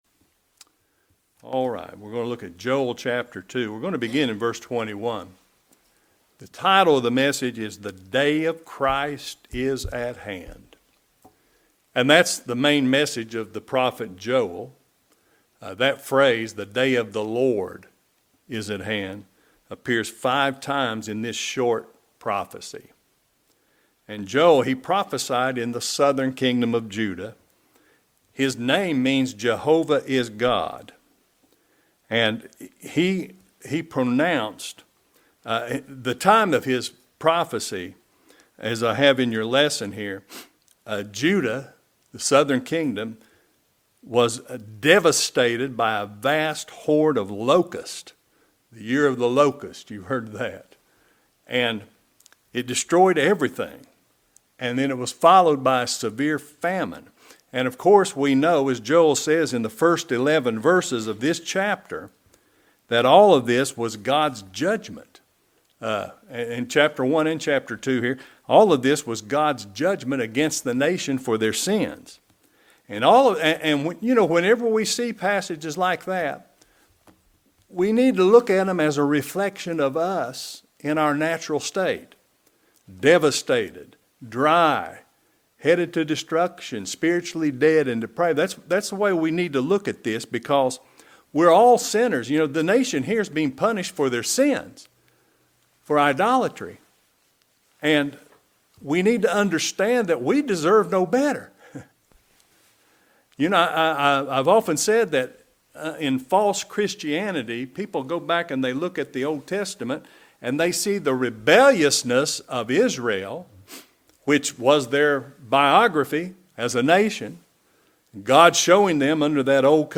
The Day of Christ is at Hand | SermonAudio Broadcaster is Live View the Live Stream Share this sermon Disabled by adblocker Copy URL Copied!